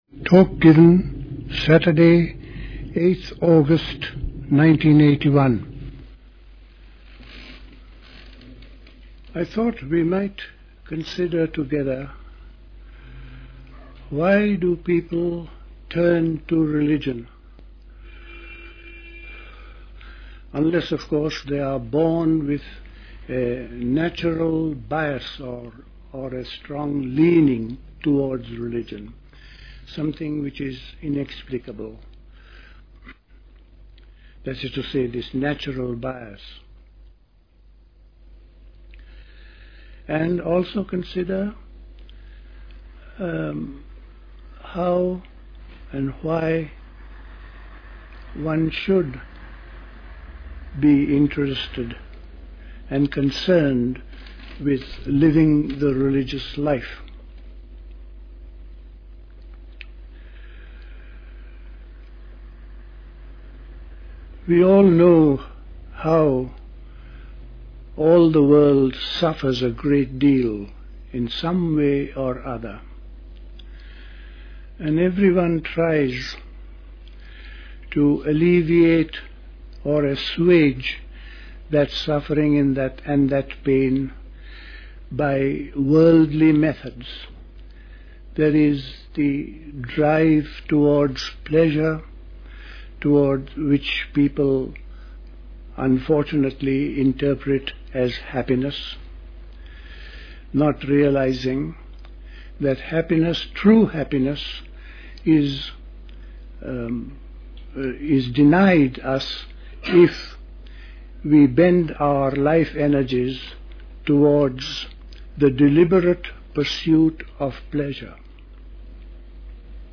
A talk
at Dilkusha, Forest Hill, London on 8th August 1981